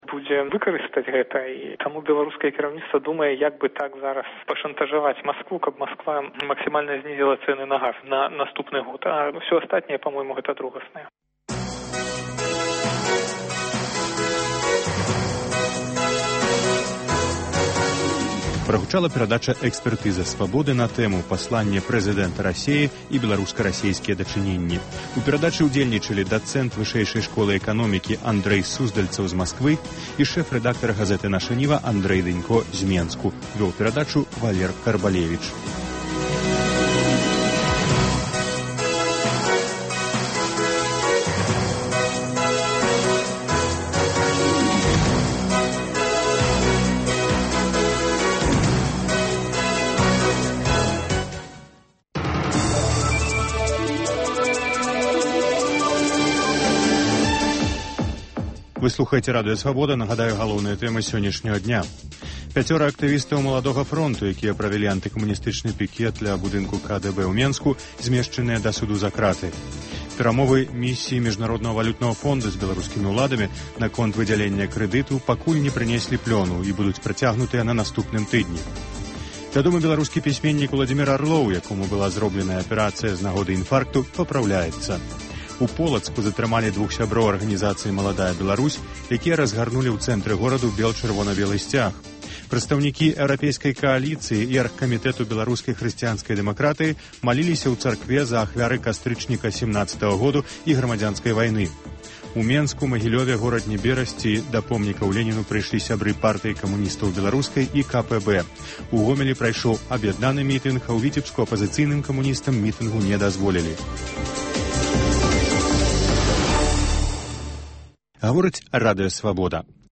гутарыць з гісторыкам